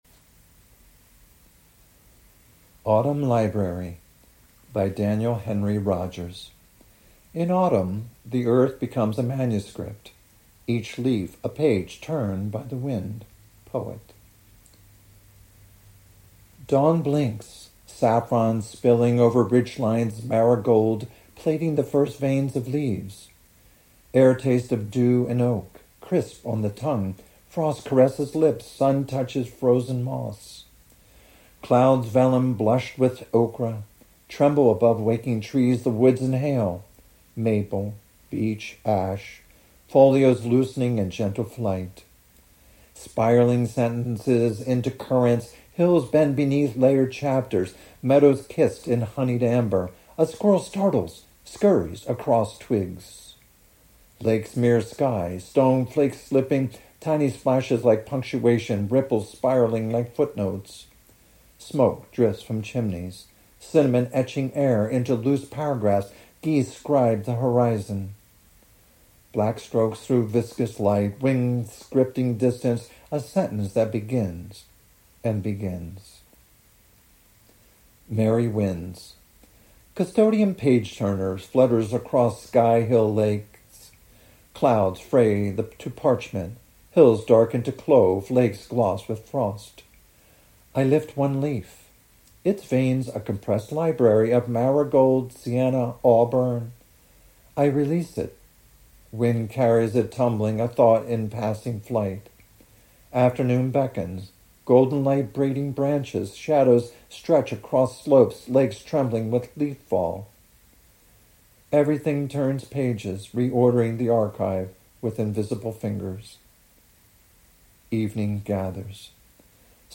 Your poem is so evocative, emotive, so beautifully written, your voice the perfect accompaniment.
With hands clapping, I applaud your spoken word with kept mellifluous flow, and how cool is that.